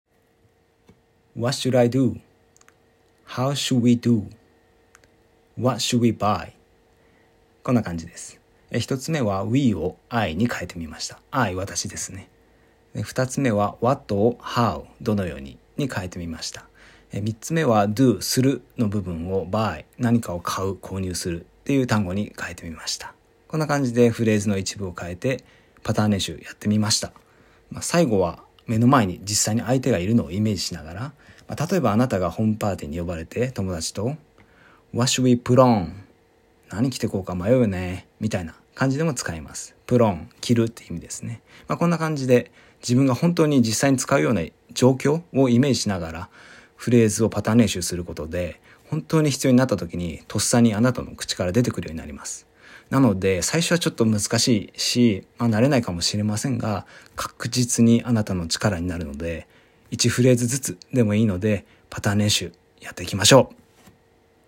この録音音声のような感じで、あたかも本当に自分が実際の英会話で話ているイメージ（自分事化）をしながら、感情を込めると、なお良いです！